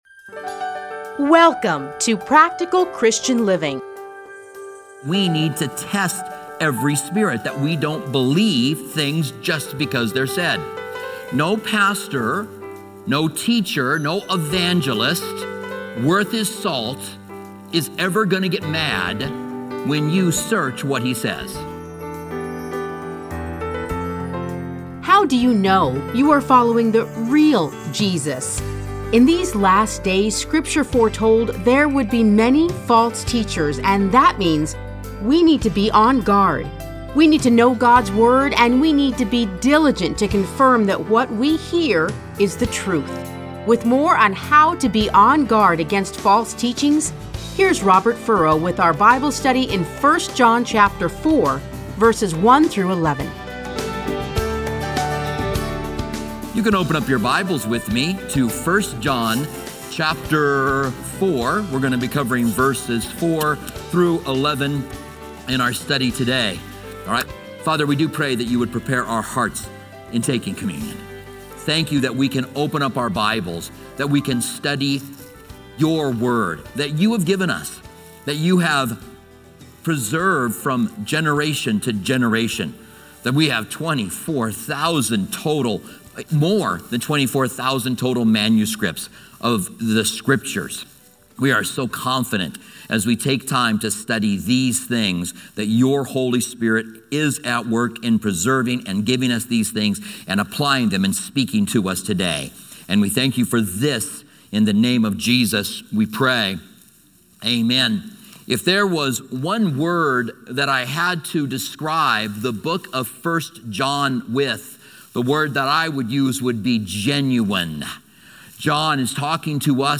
Listen to a teaching from 1 John 4:1-11.